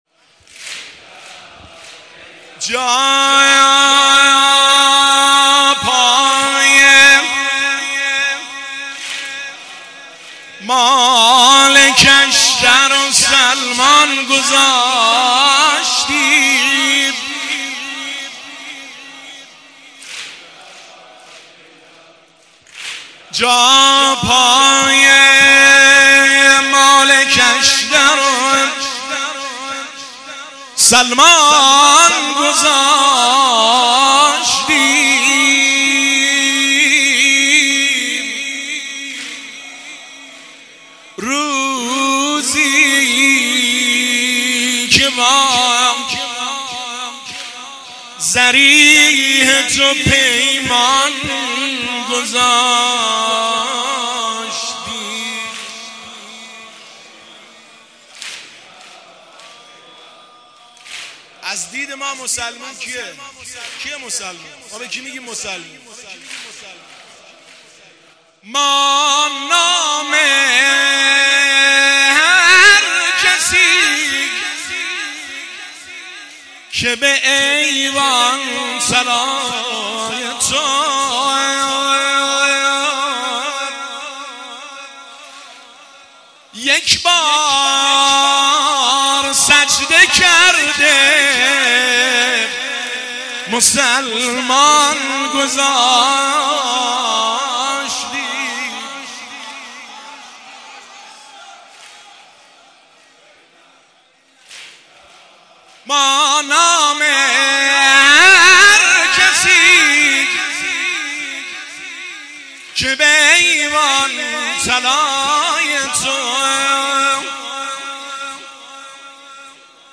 هیئت بین الحرمین طهران